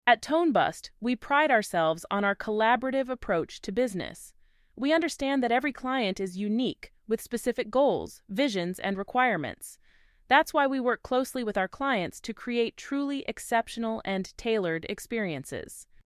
AI Voiceovers Can Enhance Your Content